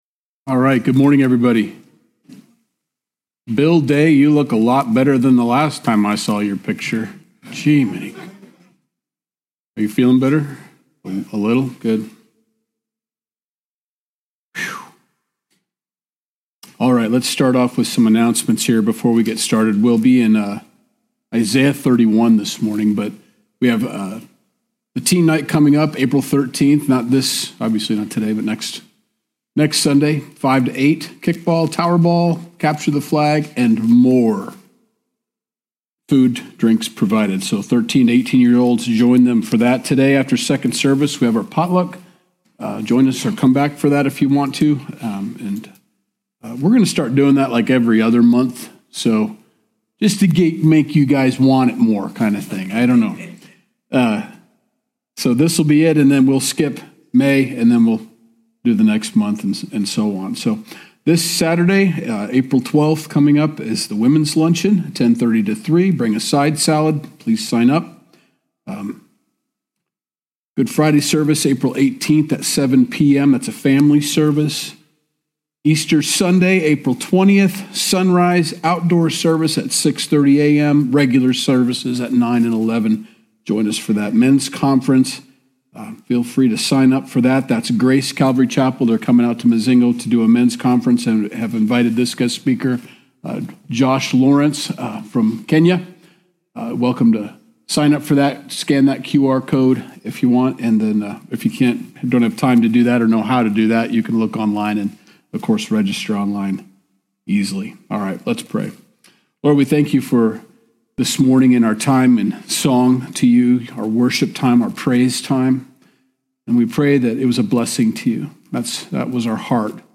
Sunday Message - March 23rd, 2025